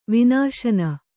♪ vināśana